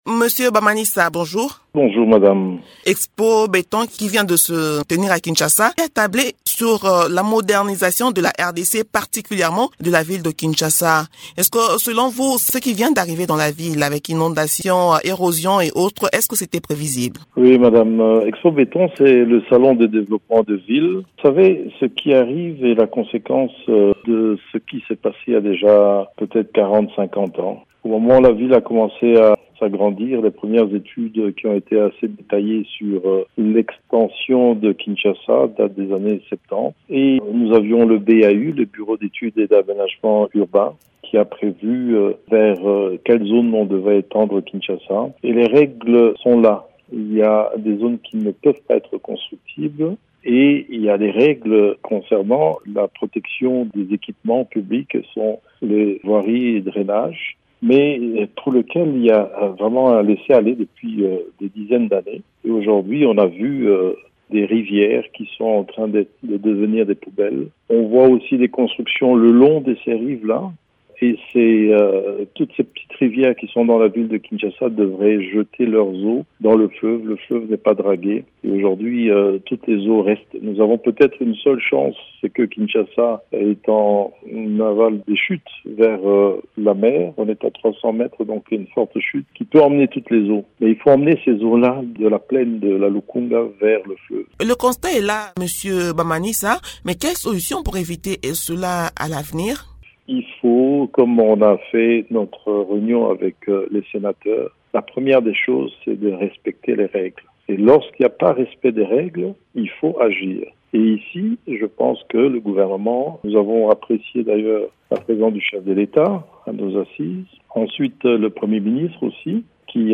Invité de Radio Okapi ce jeudi 15 décembre,  l’ancien gouverneur de l’Ituri propose le respect des règles urbanistiques pour faire face à cette catastrophe naturelle.